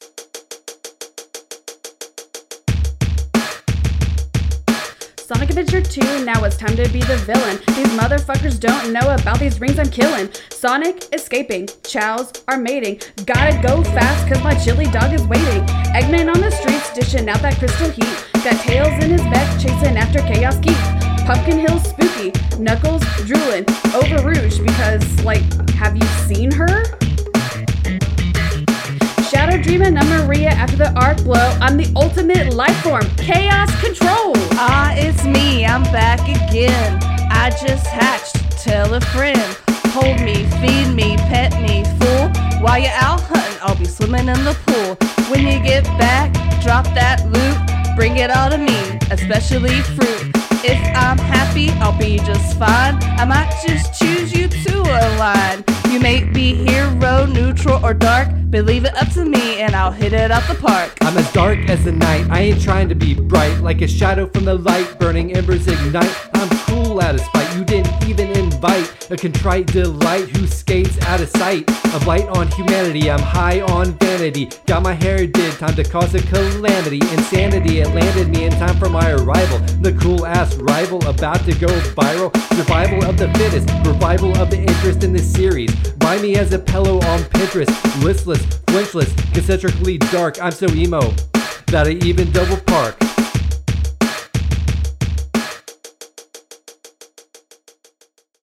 Rap from Episode 19: Sonic Adventure 2 – Press any Button